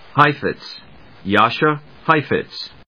/hάɪfɪts(米国英語), Jar・cha jάɚʃə(英国英語)/